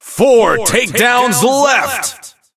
4takedownsleft_vo_01.ogg